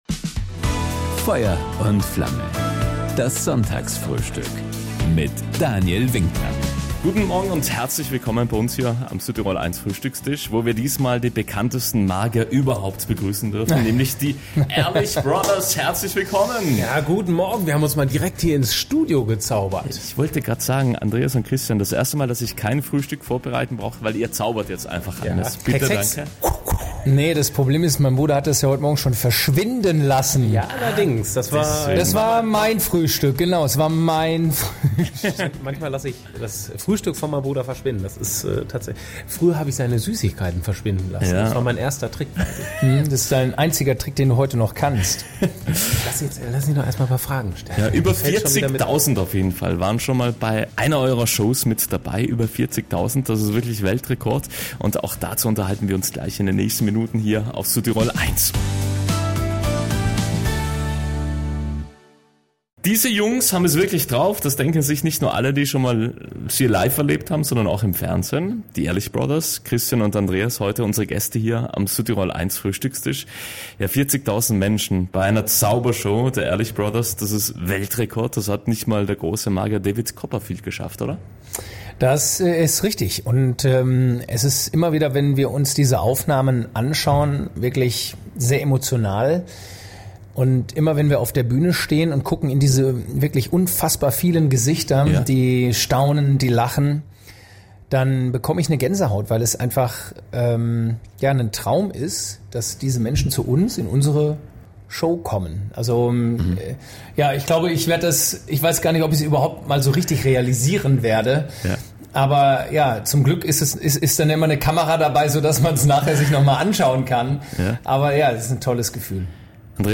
Warum das für die Brüder eigentlich gar nicht so besonders war? Auch davon erzählen sie ausführlich im Sonntagsfrühstück auf Südtirol 1.